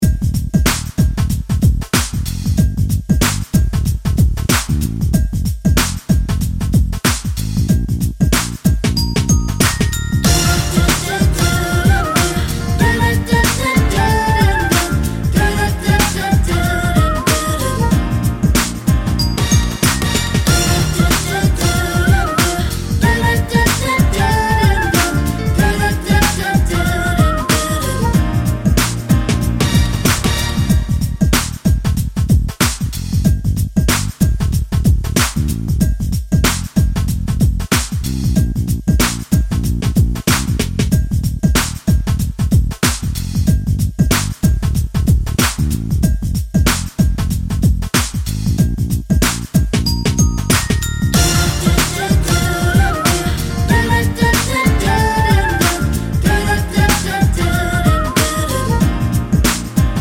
Minus Saxophone Pop (1990s) 3:51 Buy £1.50